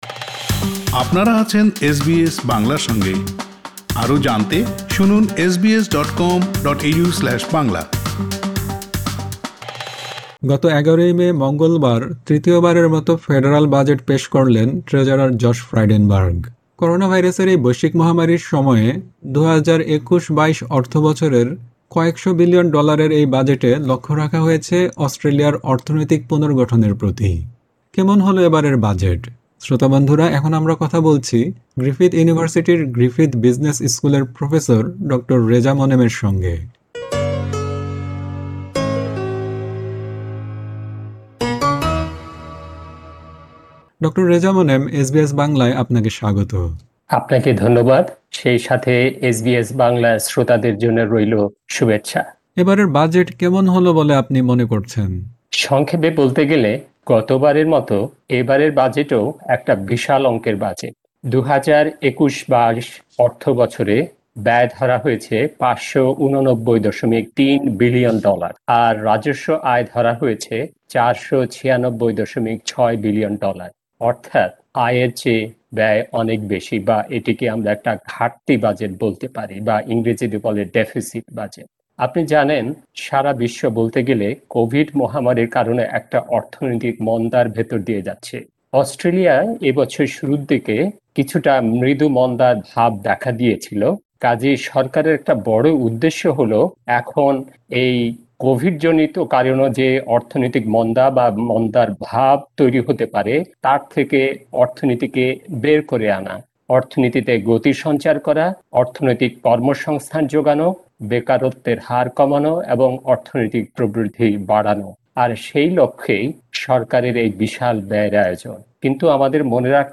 এসবিএস বাংলার সঙ্গে এ নিয়ে কথা বলেছেন…